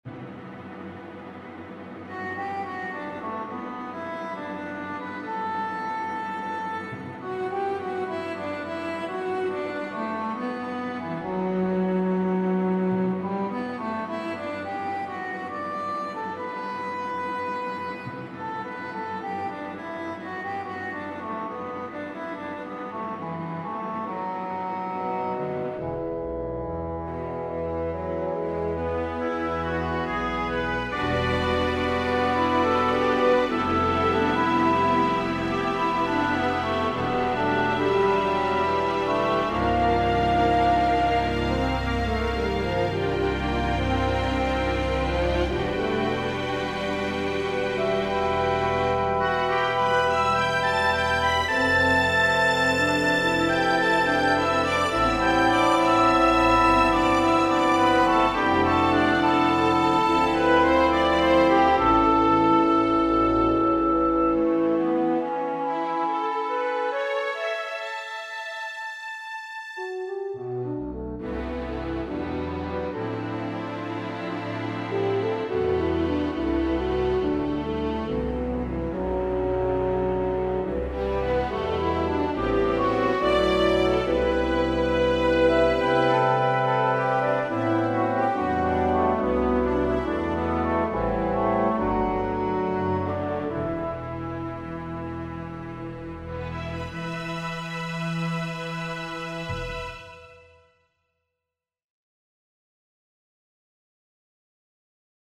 1st violins, 2 violins, violas, solo cello, cellos, basses .